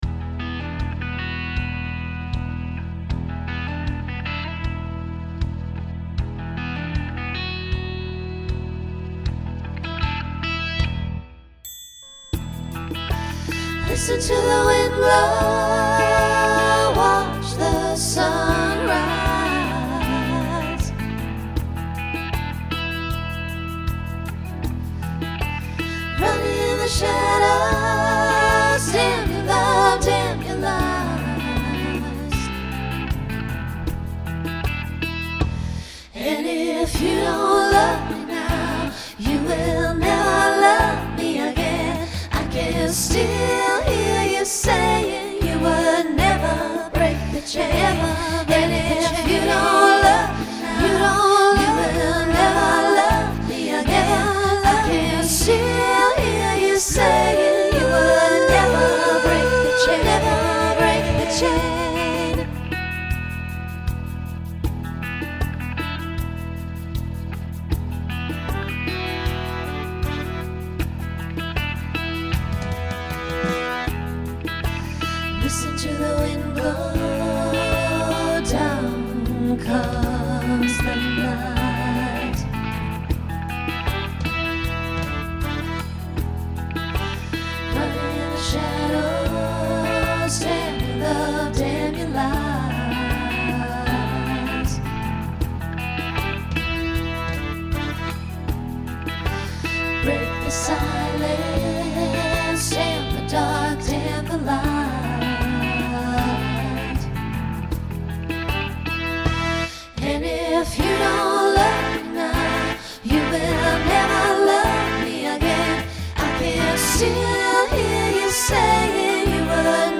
Decade 1970s Genre Rock
Transition Voicing SATB